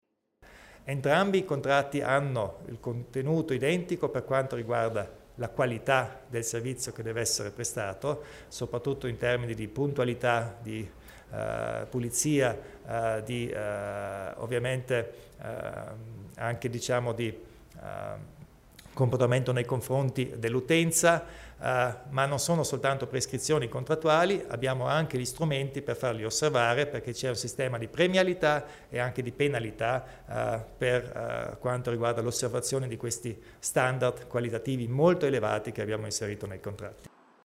Il Presidente Kompatscher spiega i dettagli dei nuovi contratti di servizio nel settore ferroviario